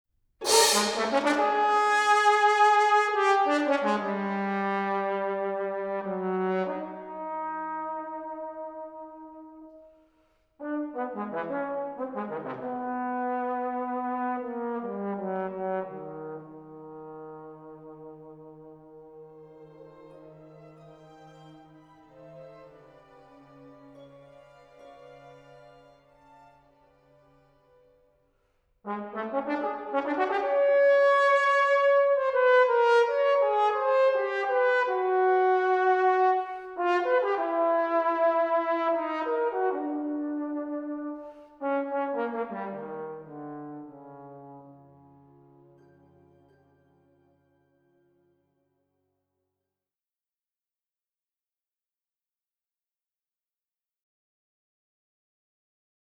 Posaune
Blechblasinstrumente
Die Posaune ist eines der tiefen Blechblasinstrumente.
29-Posaune.mp3